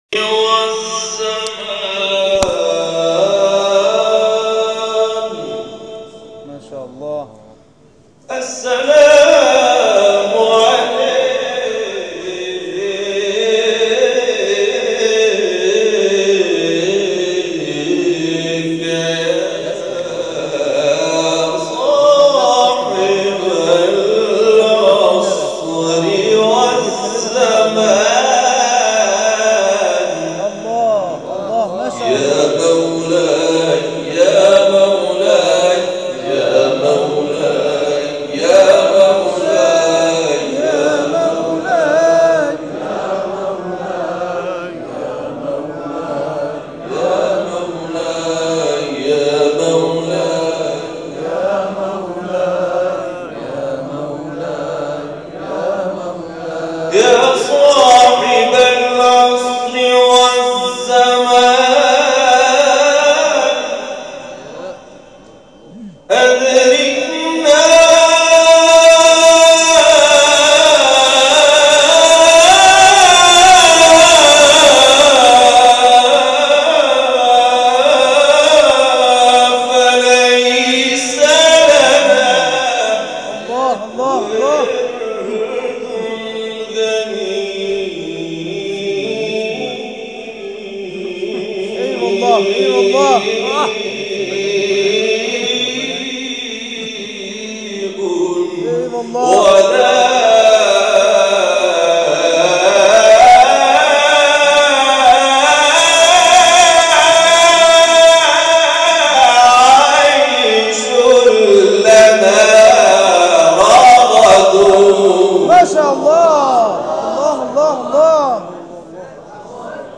ابتهال «یا صاحب العصر»